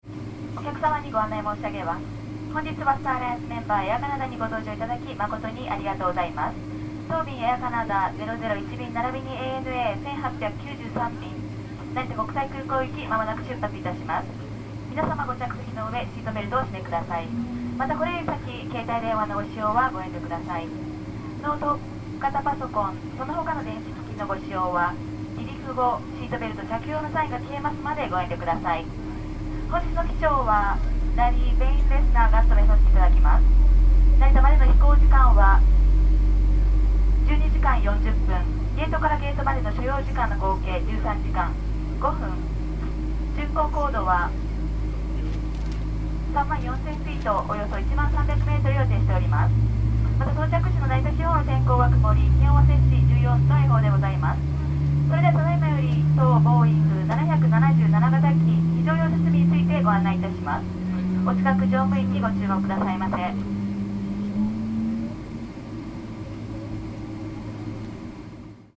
Женский голос объявляет что-то на японском в самолете